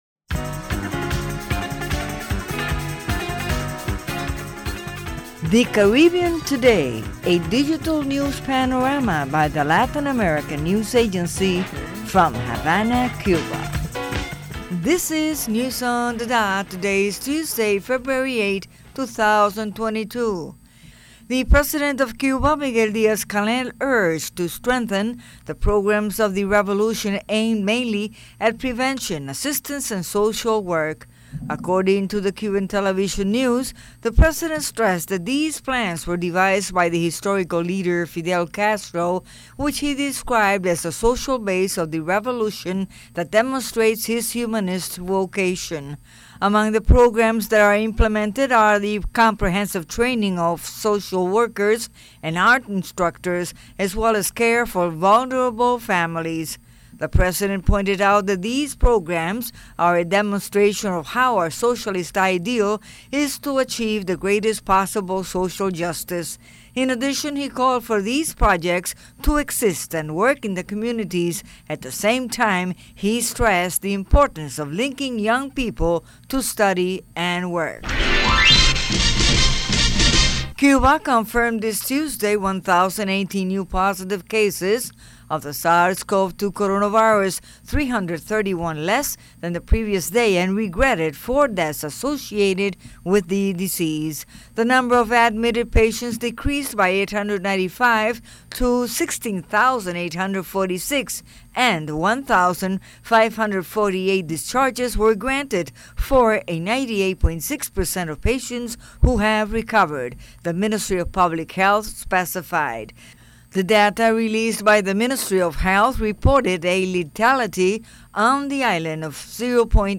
February 8, news on the dot